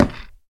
creaking_heart_place1.ogg